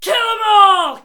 battle-cry-3.ogg